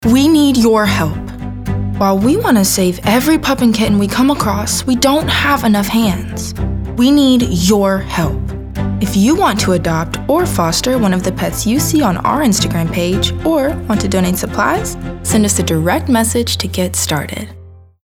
caring, concerned, conversational, friendly, genuine, informative, inspirational, real, serious, teenager, thoughtful, warm